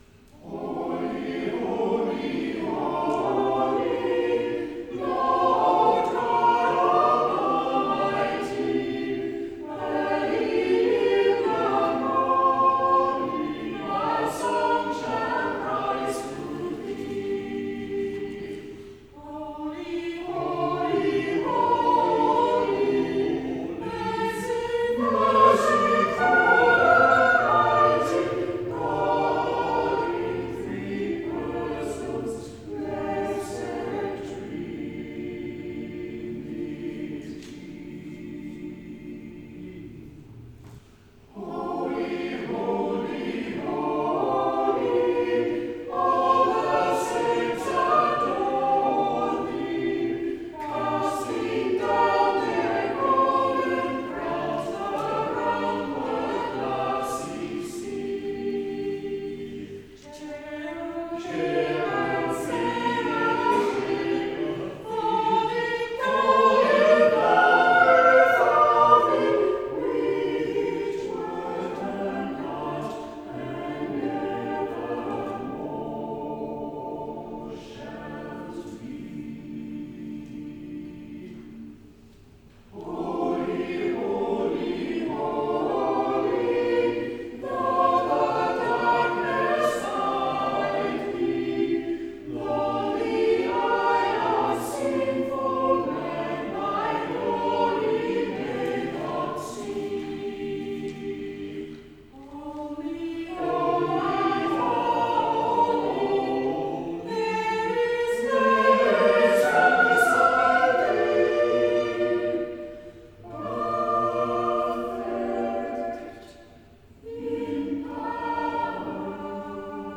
Communion motet for Trinity